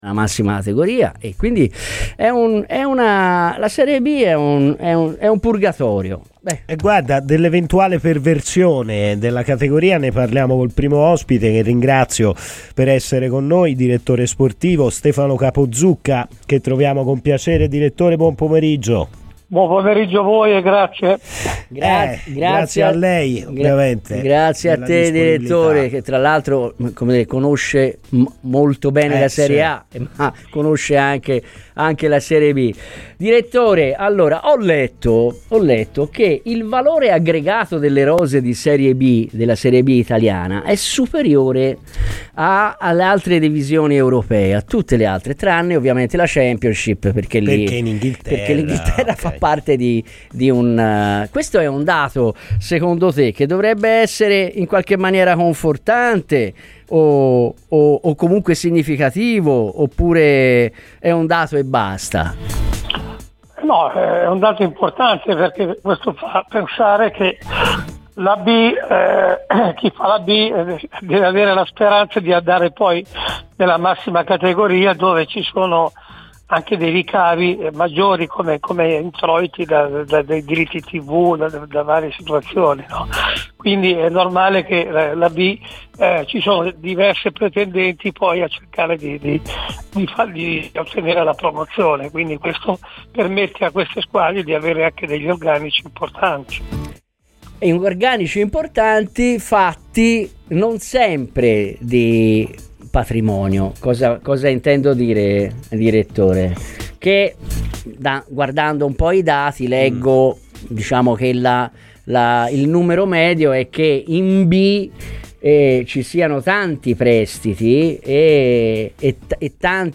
è intervenuto a Radio FirenzeViola durante Scanner